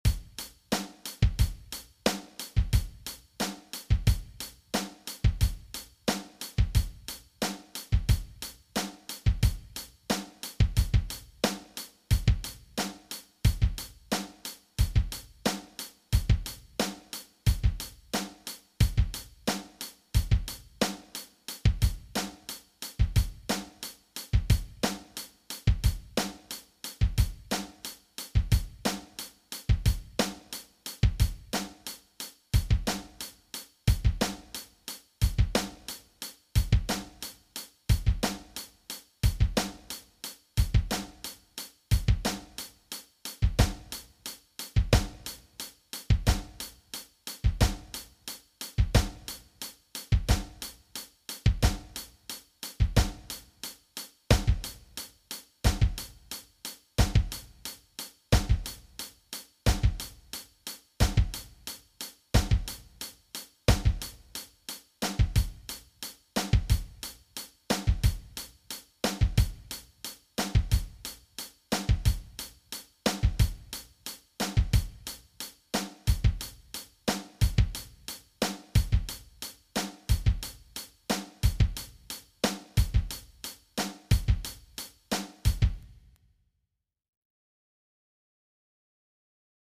16分のダブルを16分ずつ後ろにずらしていくバリエーションです。
BPM=90
moeller_foot_v2.mp3